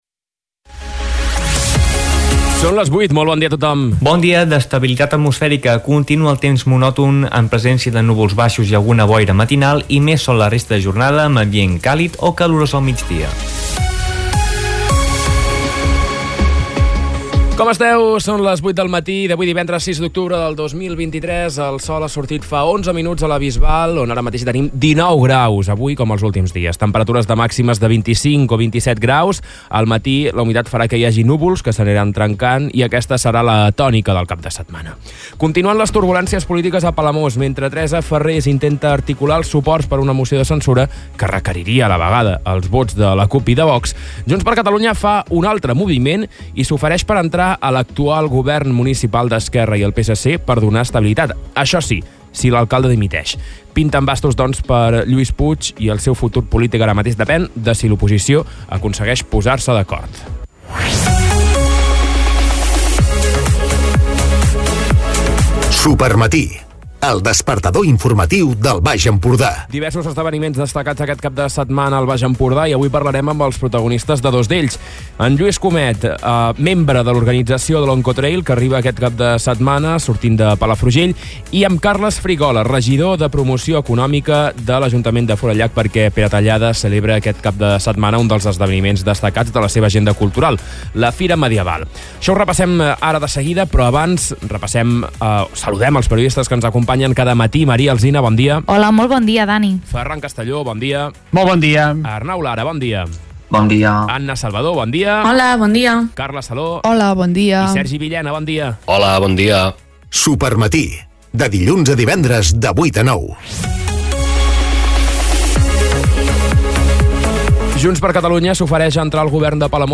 Escolta l'informatiu d'aquest divendres